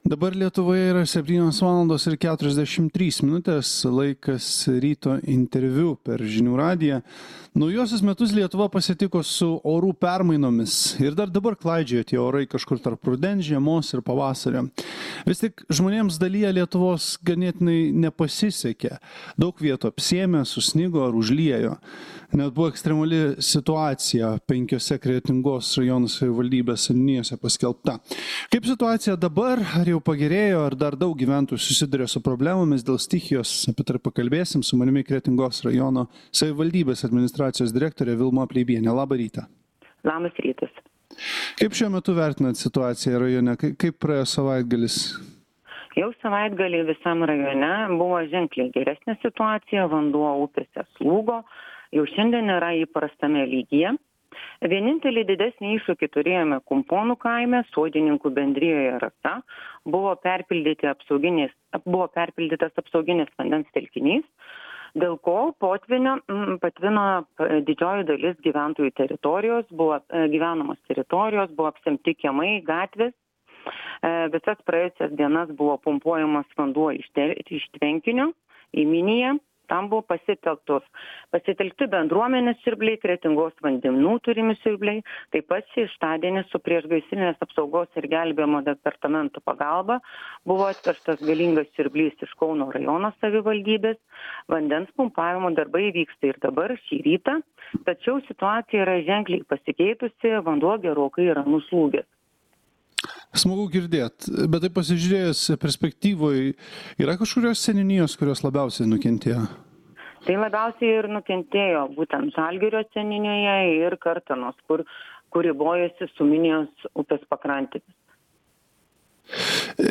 Pokalbis su Kretingos rajono savivaldybės administracijos direktore Vilma Pleibiene.